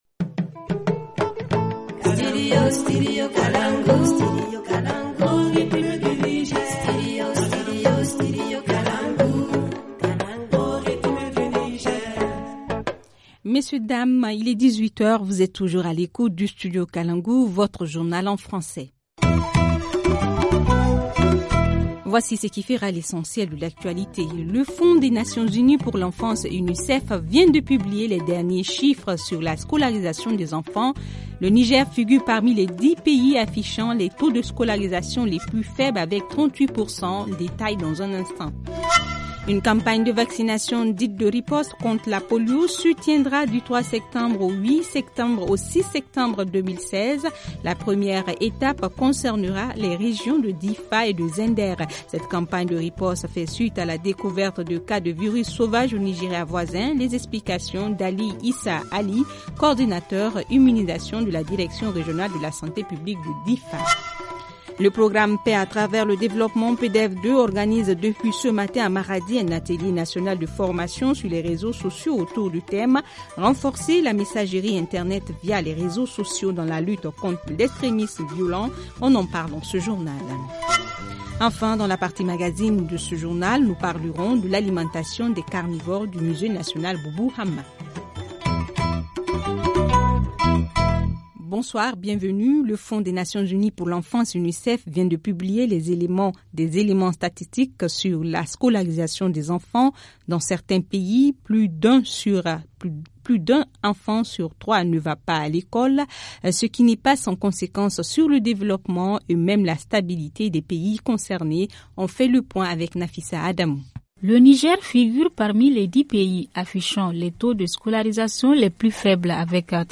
Journal en français